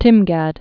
(tĭmgăd)